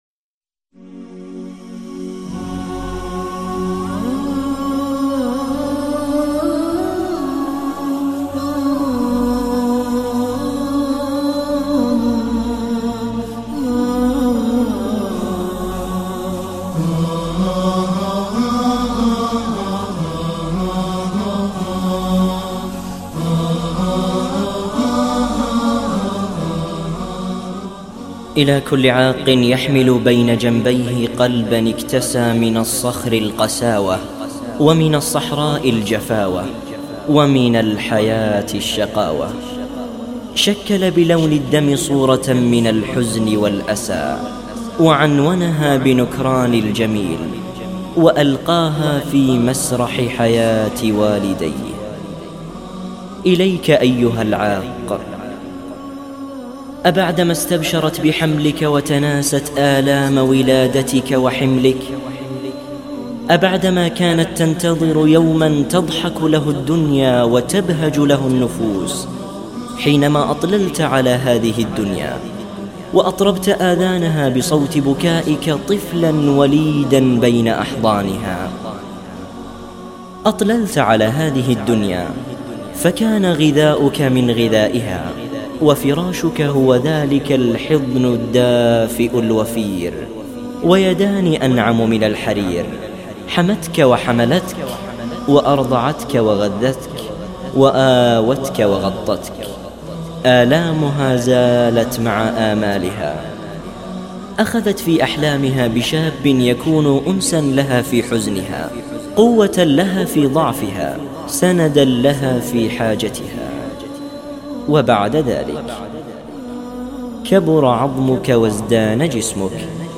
مقطع الأم جداً محزن
وأحزنتني الأم المكلومة التي تشتكي من ظلم ابنها ..
يحمل الصوت الشجي والمؤثر ، ويملك الكلمات الرقيقة والمميزة ..
اتصال الأم مبكي : (